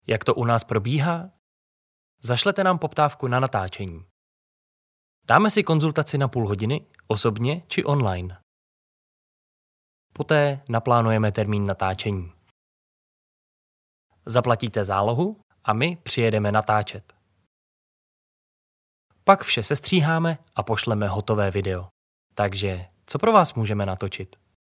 Mladý energický mužský hlas přesně pro Vás!
Můžu použít více energický projev nebo klidnější polohu, stačí si jen vybrat.
Videoreklama ANIMACE_1.mp3